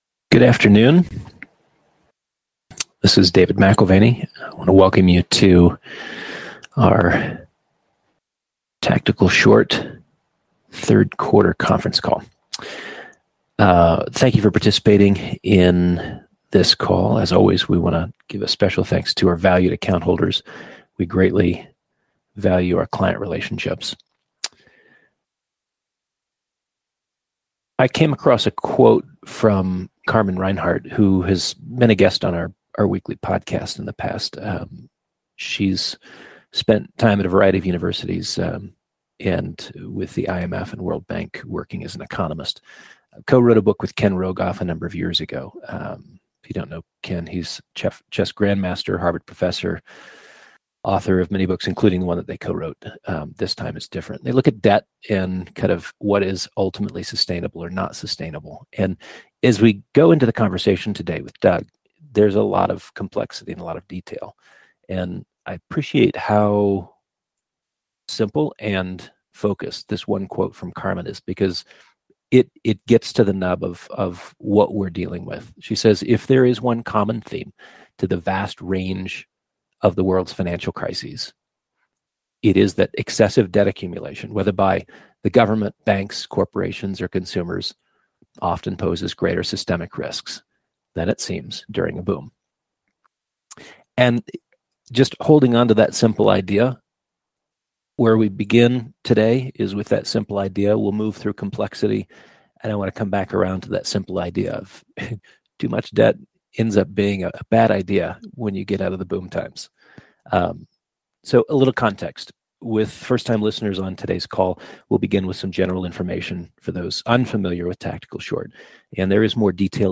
Late-Cycle Bubble Fragilities MWM Q3 2024 Tactical Short Conference Call October 31, 2024